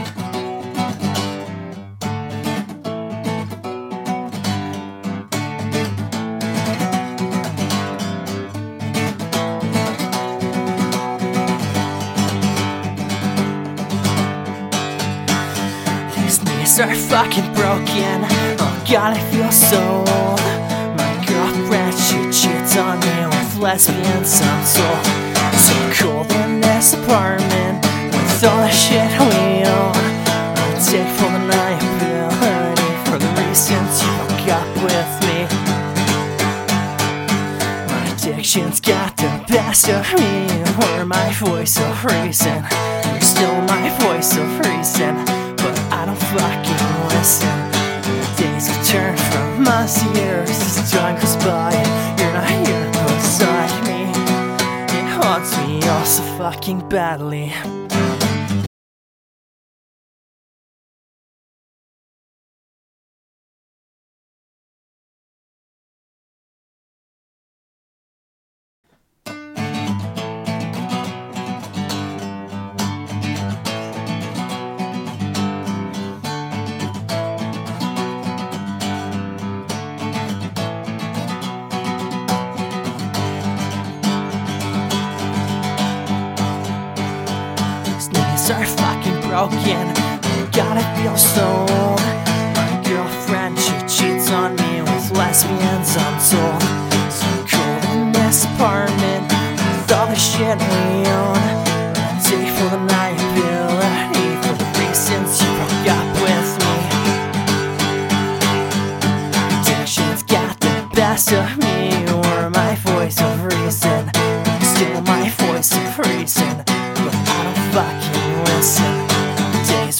I posted in another section and was told i'm way to breathy when i sing so i tried to fix it and will continue to do so because i now hear it when i look for it.
I have better "performances" than this, i just wanted something fresh. i sang the first take normal and then the second take i try and not sound so breathy. the breathy take i did in one recording the second recording i did like 15 takes on...